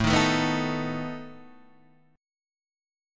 Asus2b5 chord {5 6 7 4 4 5} chord